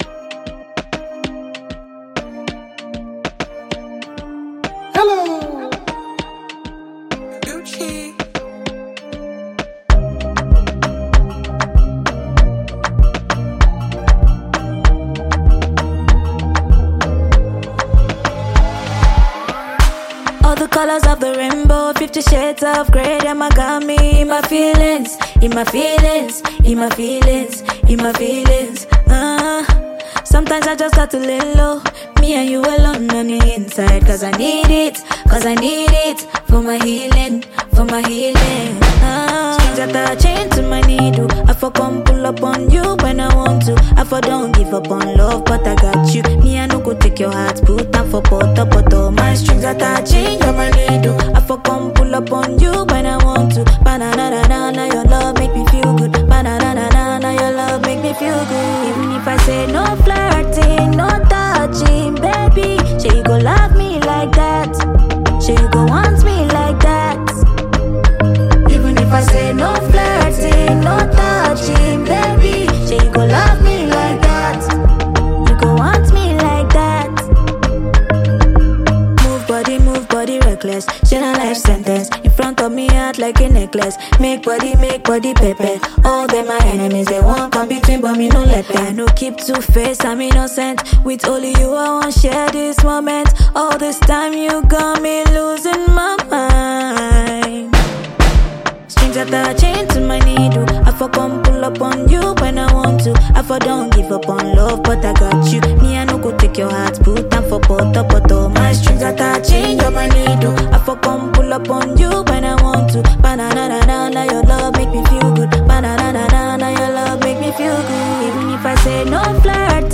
Nigerian Afropop sensation
refreshing hit song